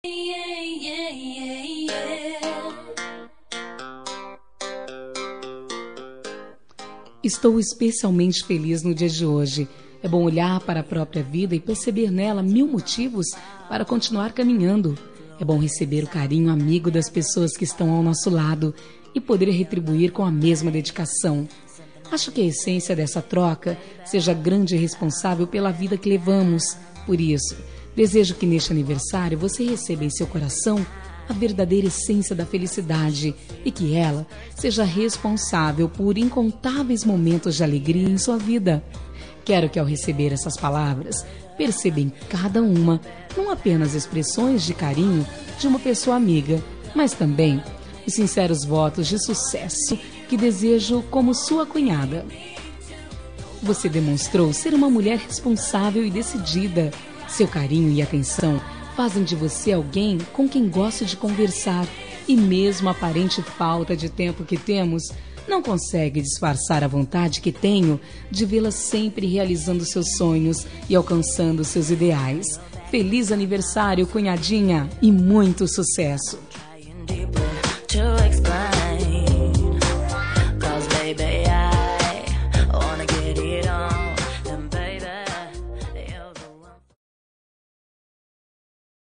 Aniversário de Cunhada – Voz Feminina – Cód: 2624